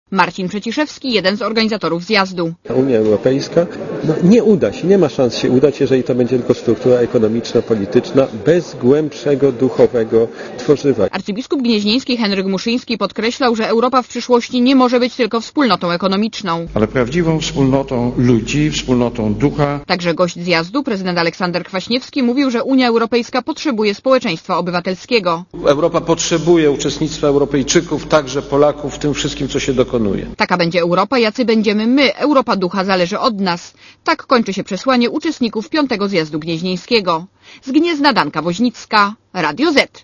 Posłuchaj relacji reporterki Radia Zet (155 KB)